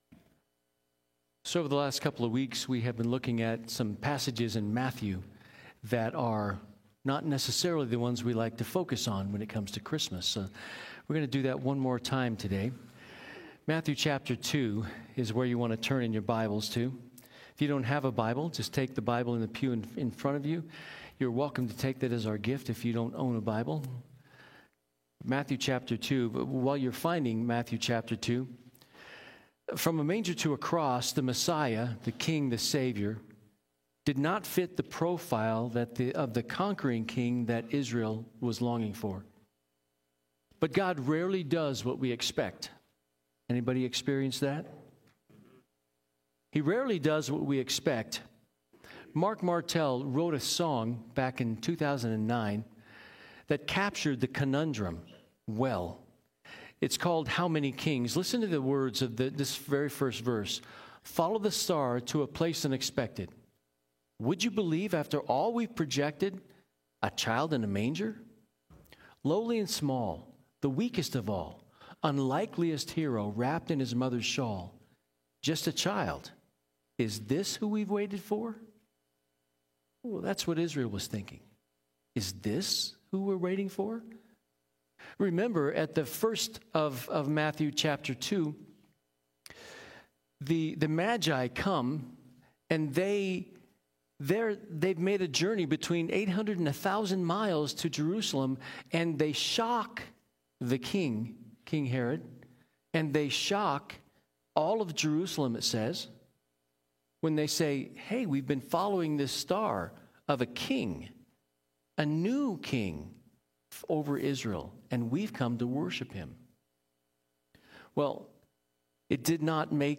Sermons | First Baptist Church of Golden
Candlelight Service